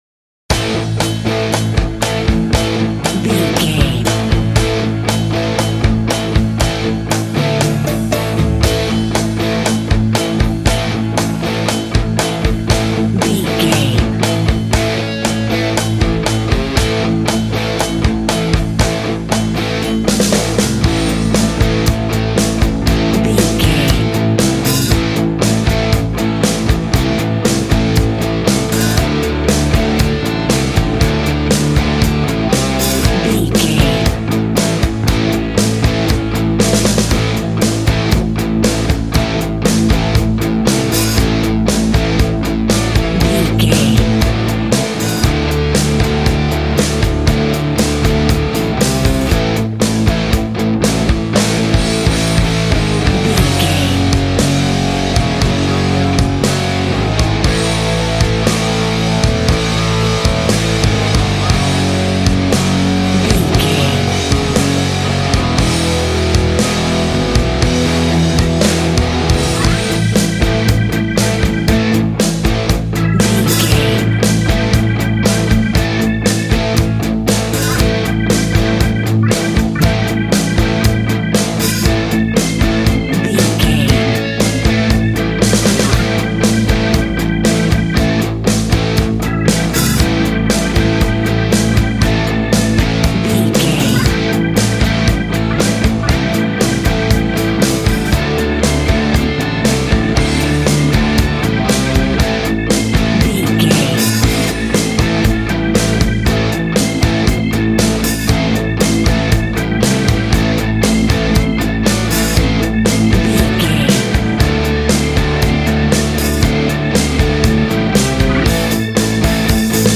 Ionian/Major
groovy
powerful
electric guitar
bass guitar
drums
organ